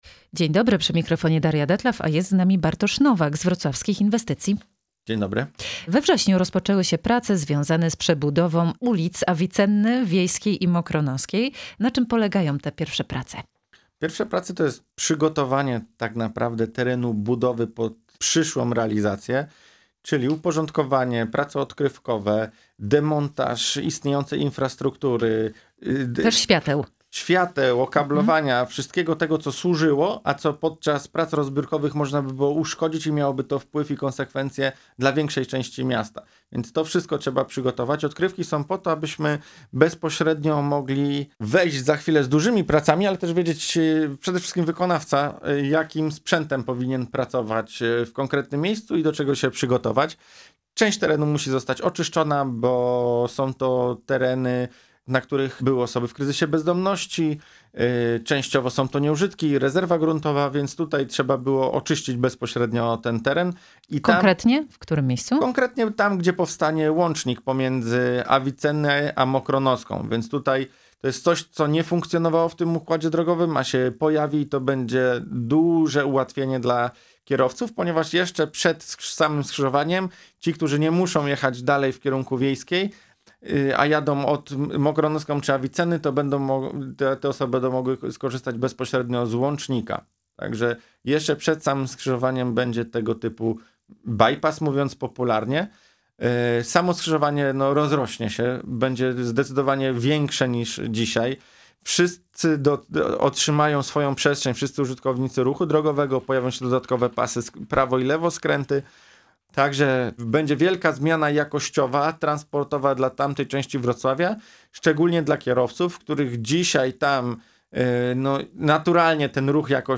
Emisja 26 września po godz. 16:00.